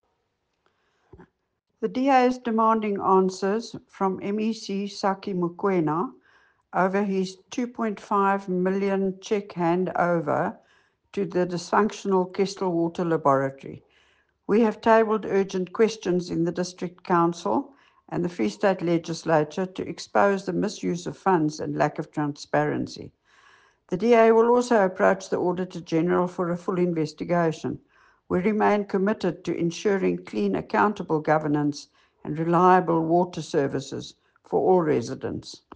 Afrikaans soundbites by Cllr Leona Kleynhans and